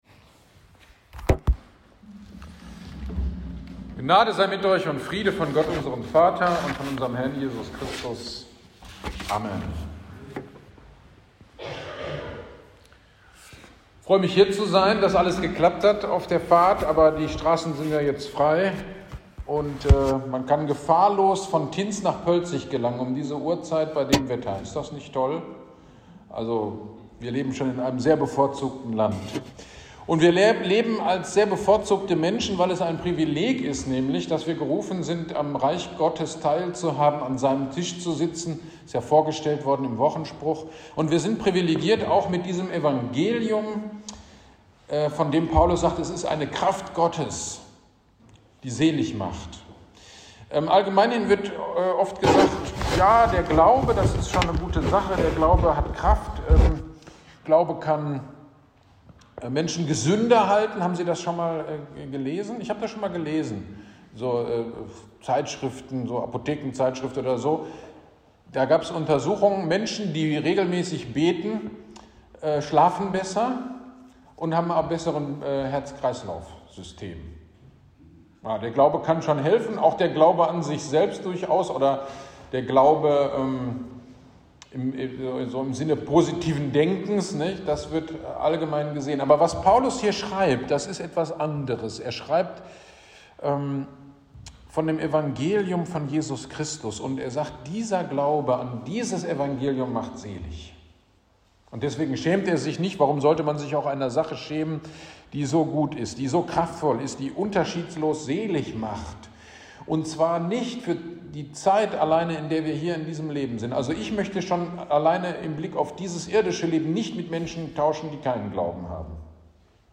Gottesdienst am 22.01.23 Predigt zu Römer 1.13-17